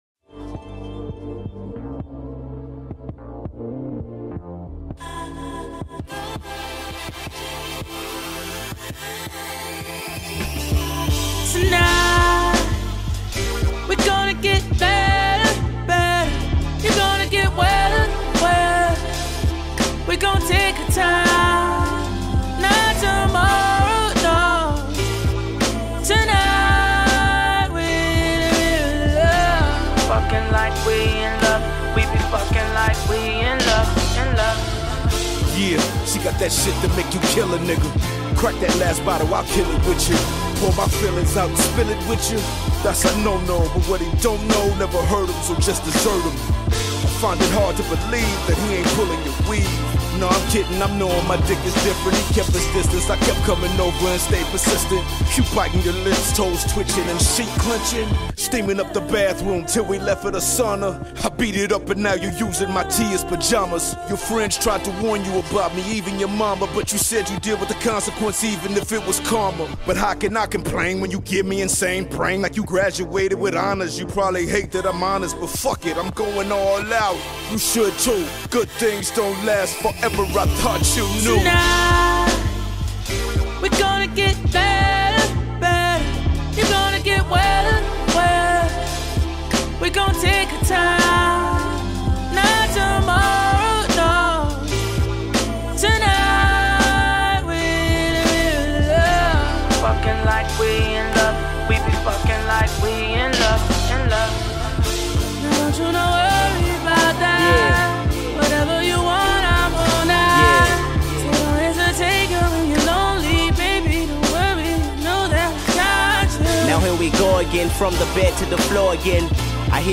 Hip-Hop News